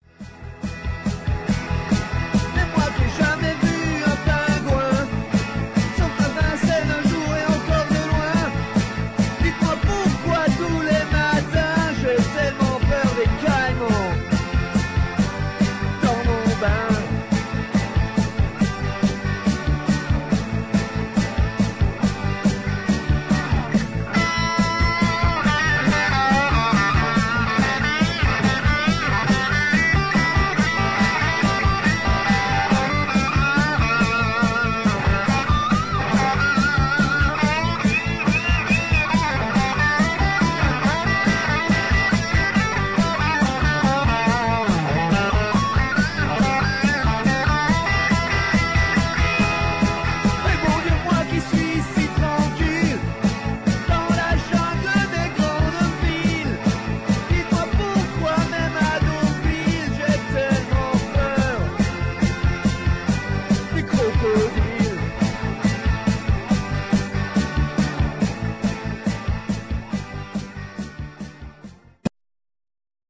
rock'n'roll avant tout